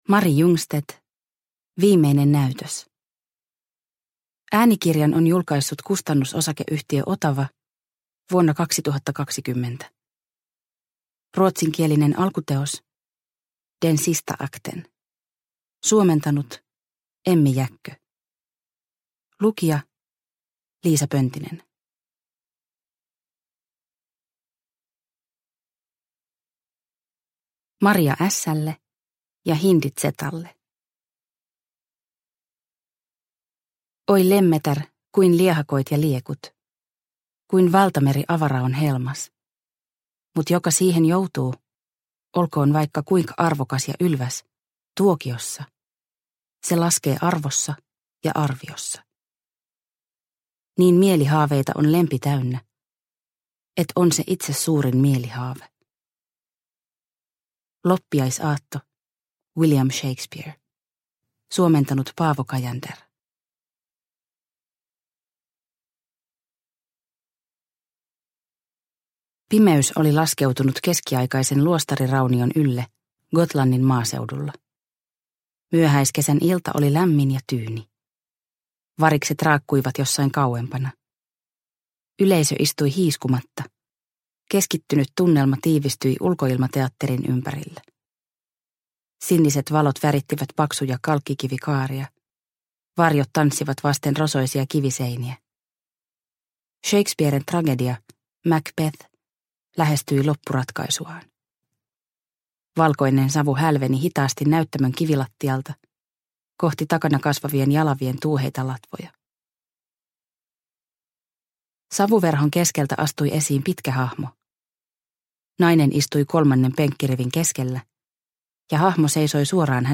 Viimeinen näytös – Ljudbok – Laddas ner